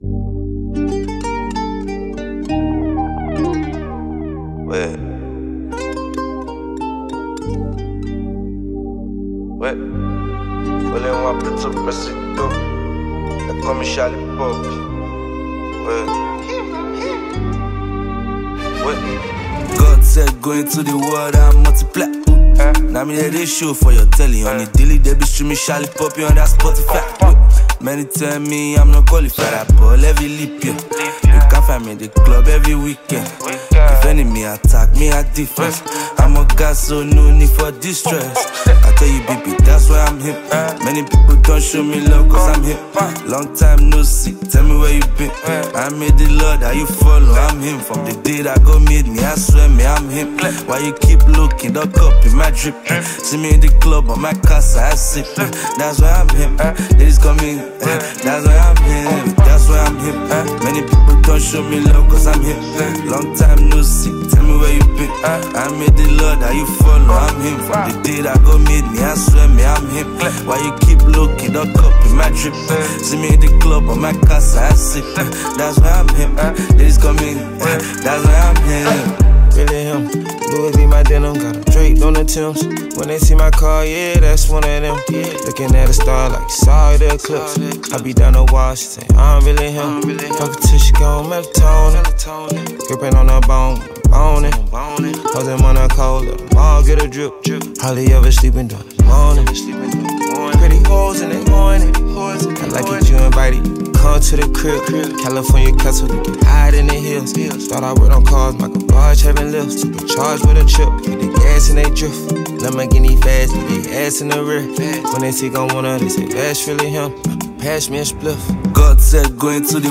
This track ill get you on the dance floor on the first note.